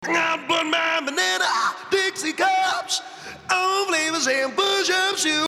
Here's the isolated vocals for that section